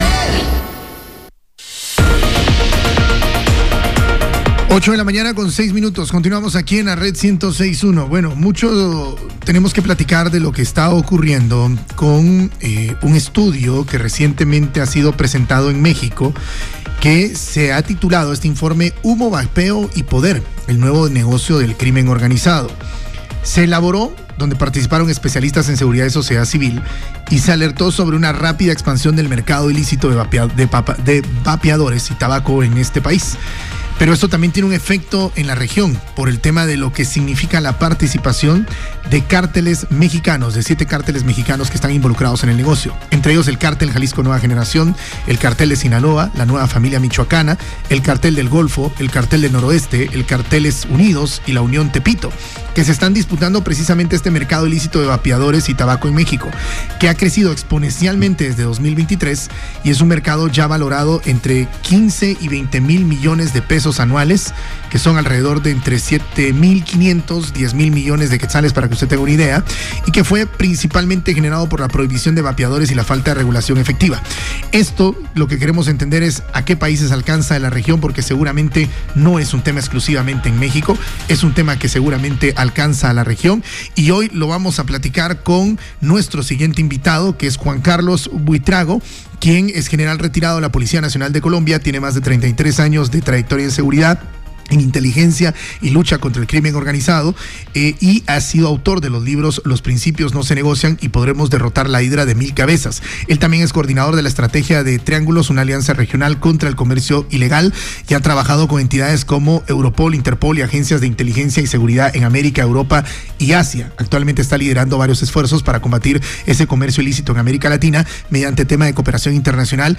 En una reciente entrevista con La Red Noticias GT